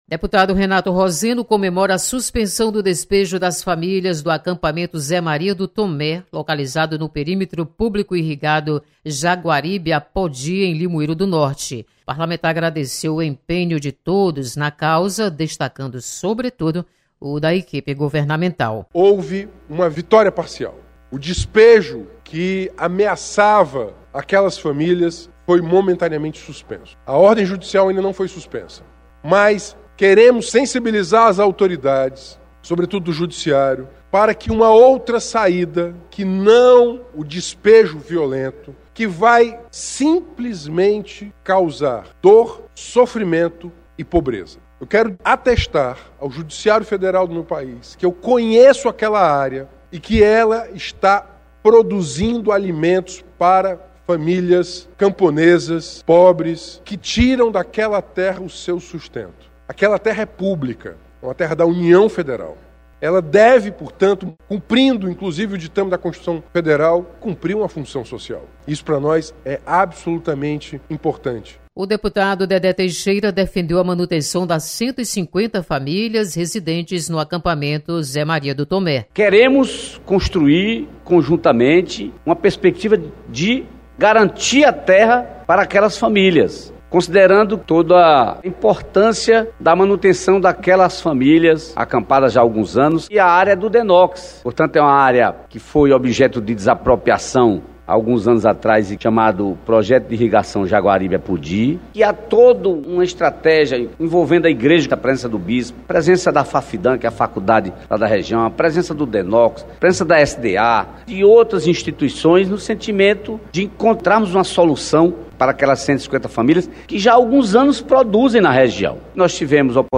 Deputado Renato Roseno comemora suspensão de despejo de famílias acampadas. Repórter